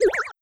Water4.wav